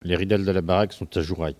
Maraîchin
Locution